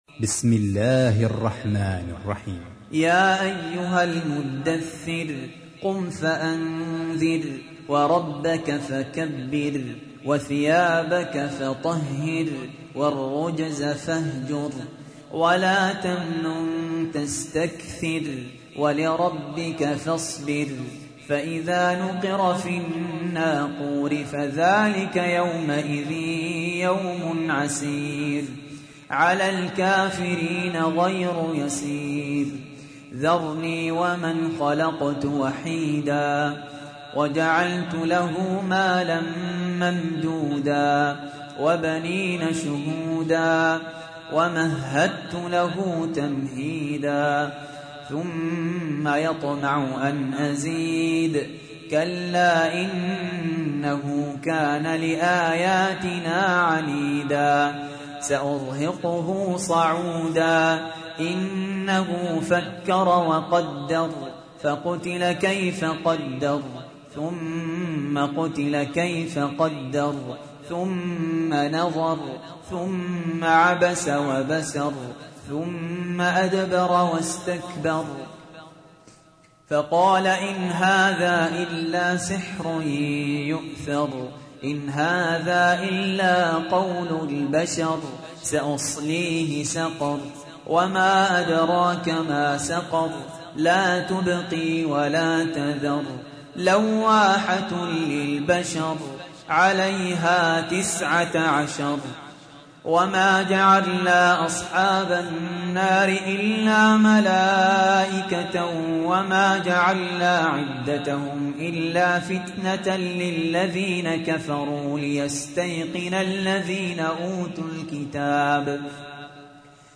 تحميل : 74. سورة المدثر / القارئ سهل ياسين / القرآن الكريم / موقع يا حسين